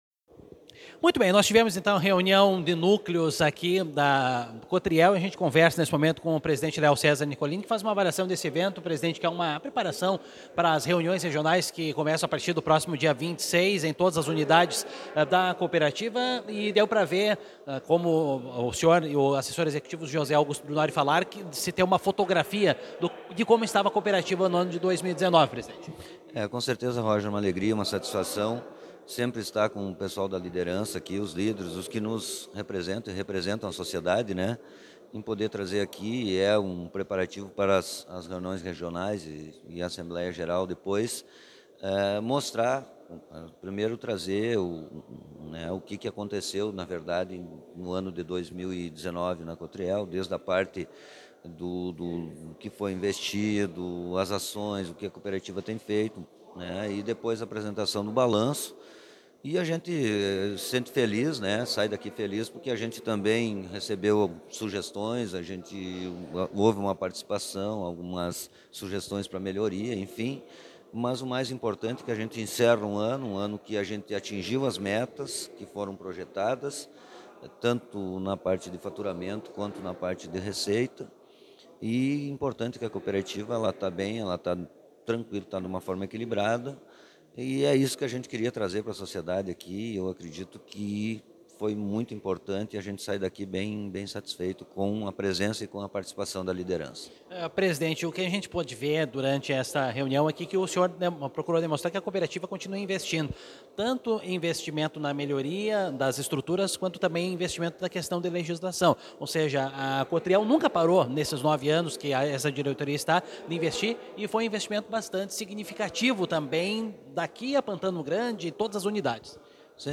aqui a avaliação da reunião feita pelo presidente